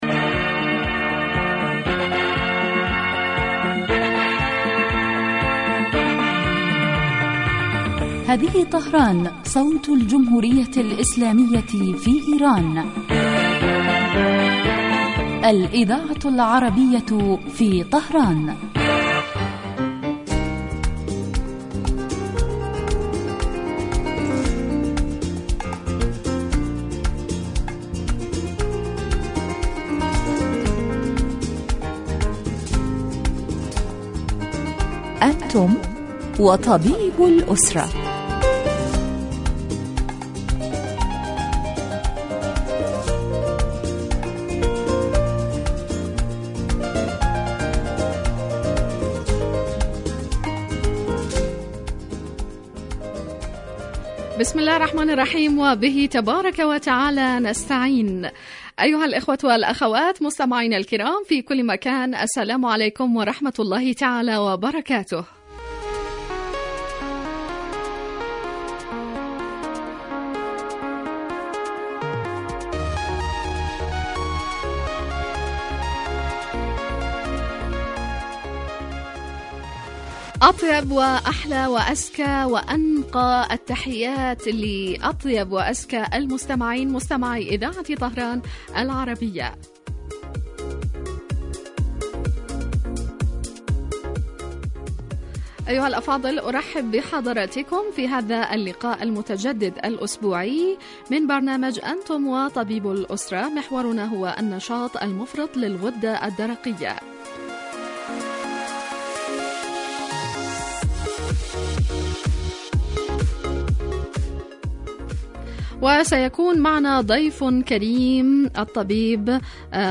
يتناول البرنامج بالدراسة و التحليل ما يتعلق بالأمراض و هو خاص بالأسرة و يقدم مباشرة من قبل الطبيب المختص الذي يرد كذلك علي أسئلة المستمعين و استفساراتهم الطبية